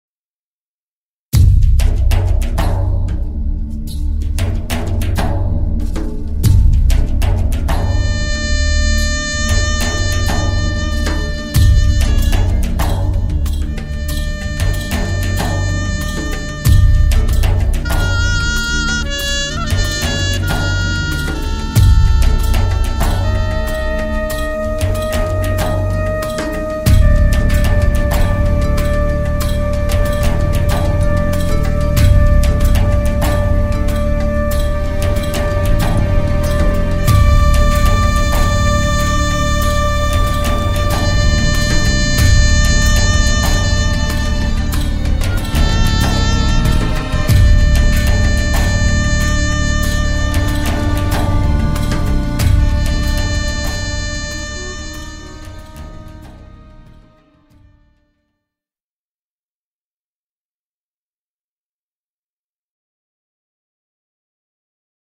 [Artist: Instrumental ]